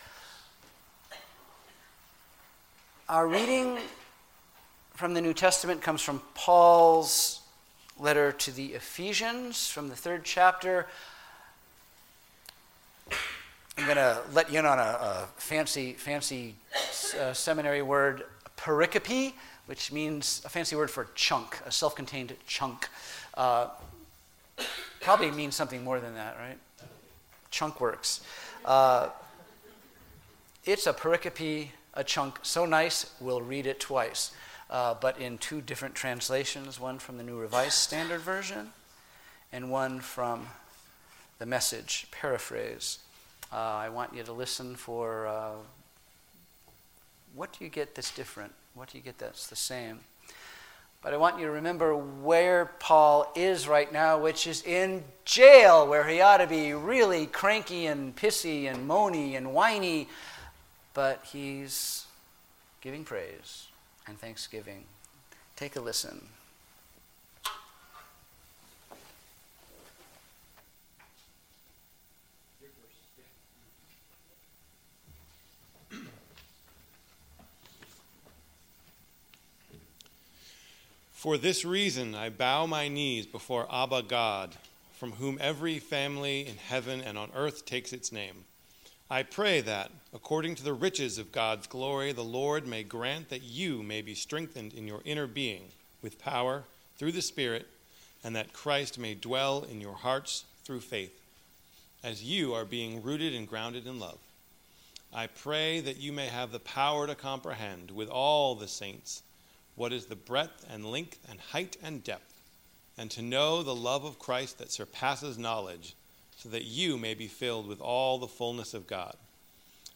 Sermons What Is God Really Like?